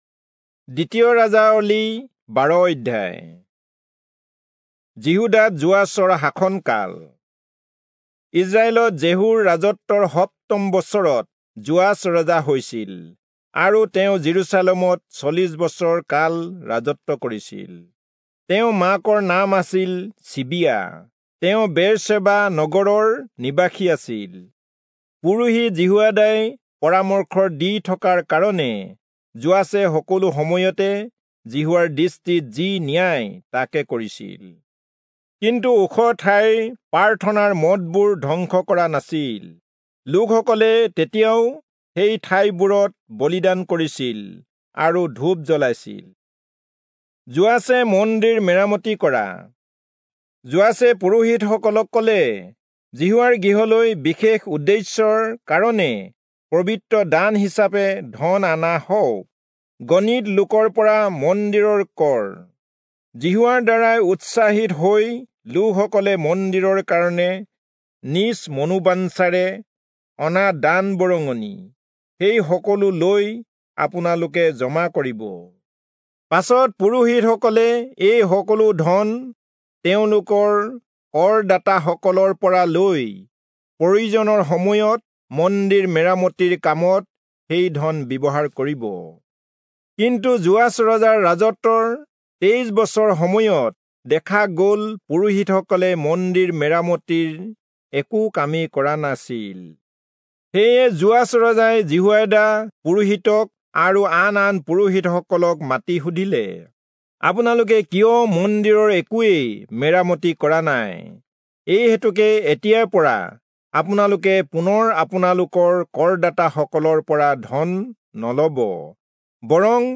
Assamese Audio Bible - 2-Kings 13 in Ocvhi bible version